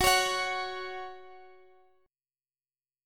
Gb5 chord